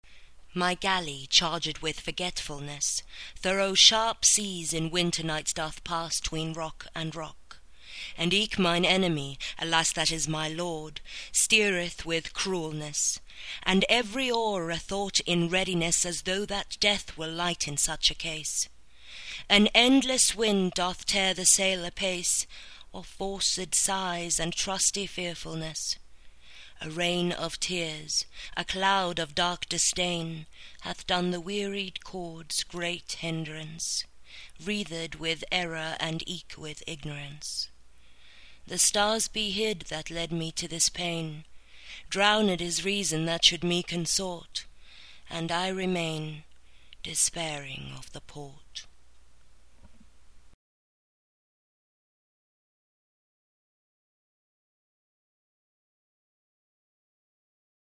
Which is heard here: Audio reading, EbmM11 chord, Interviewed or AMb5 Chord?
Audio reading